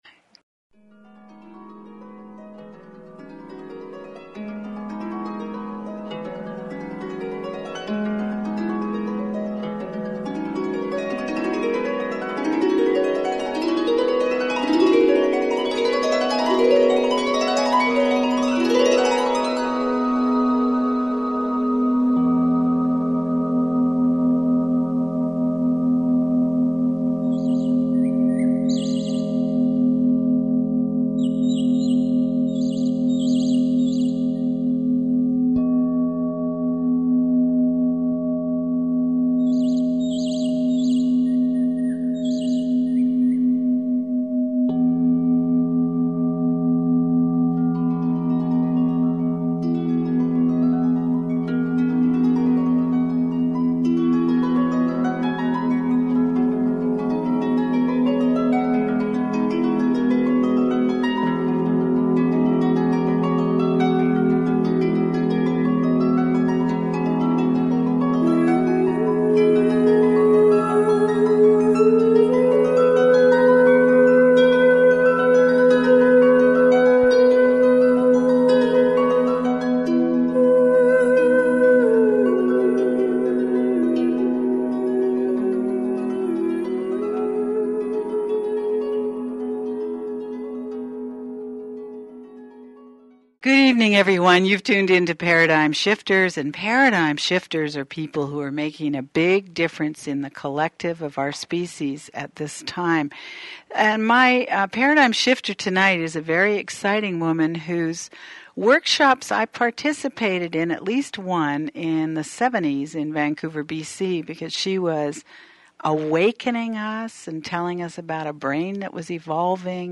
Paradigm Shifters interview